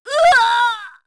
Seria-Vox_Damage_03_kr.wav